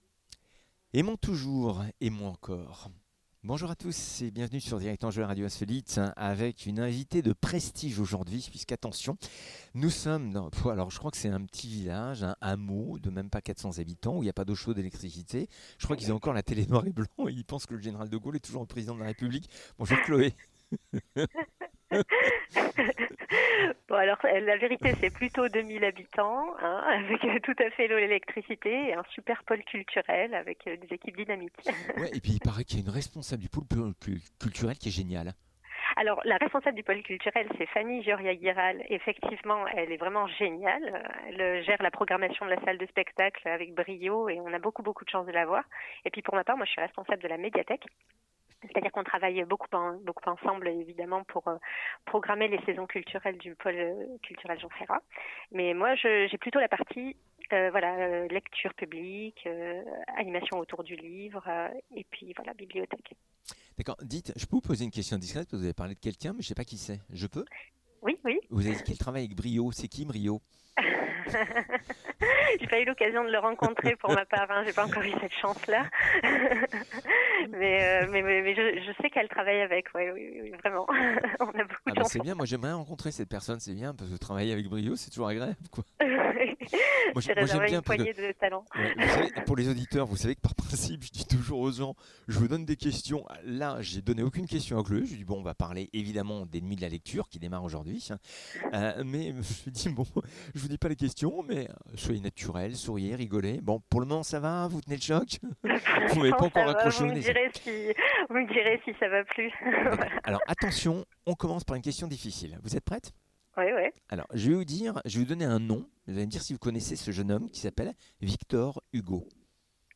pour Les Nuits de la Lecture 2022 en direct de Cheval Passion à Avignon